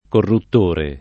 [ korrutt 1 re ]